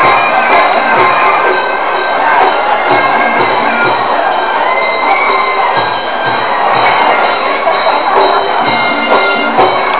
Muay Thai kickboxing at the National Stadium in Bangkok